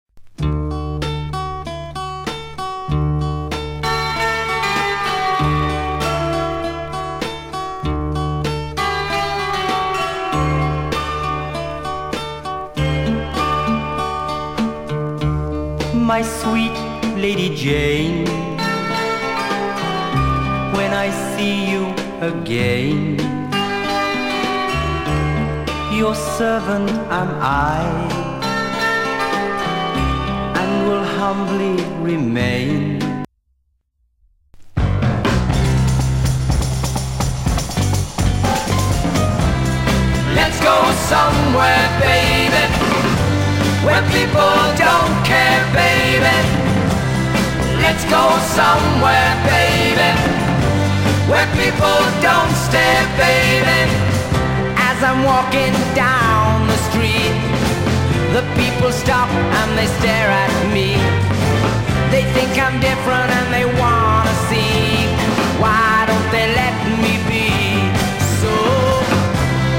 3枚目のシングルとなるこの幻想的な作品でようやくチャートイン。